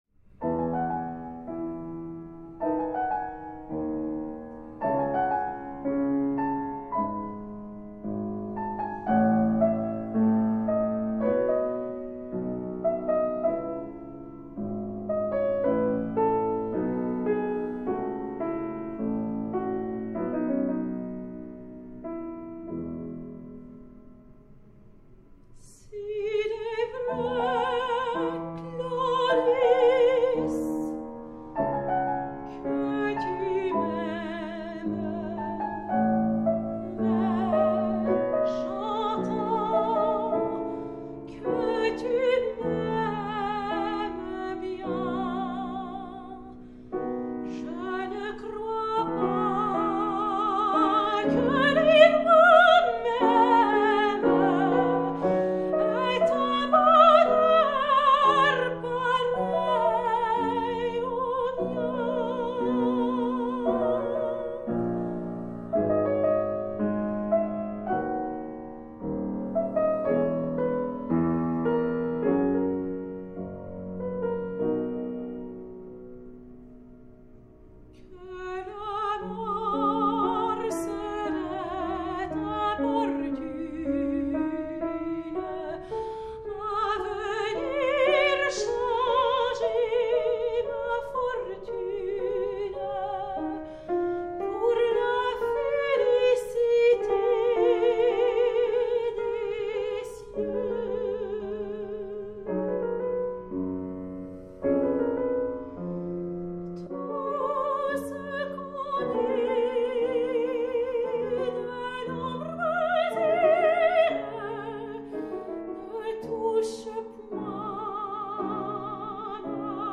piano
soprano
In the intimate and muted atmosphere of a Parisian salon, discover a city in the middle of an artistic transformation.